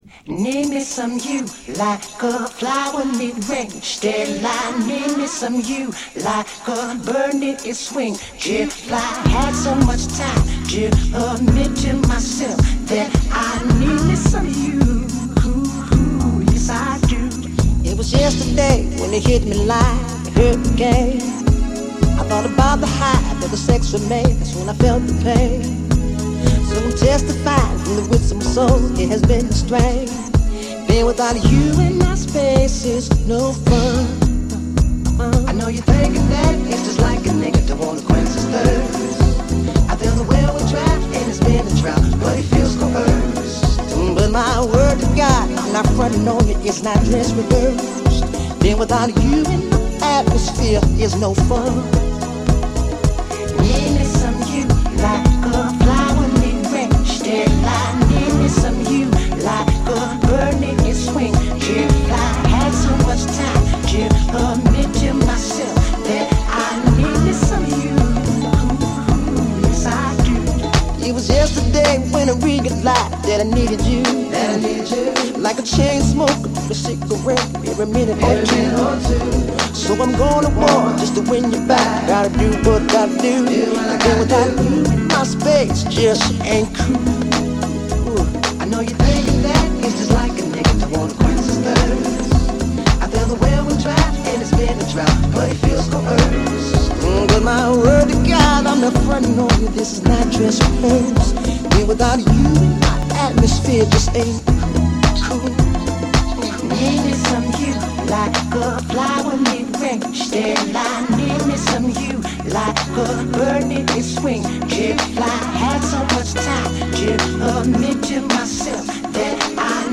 Categories: House. Techno